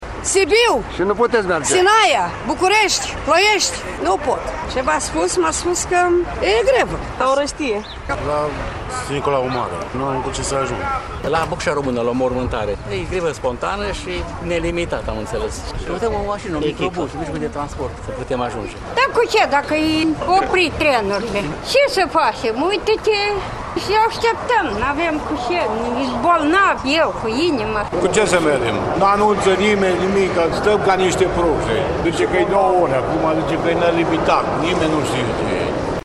22-mar-13-VOX-Calatori-Arad.mp3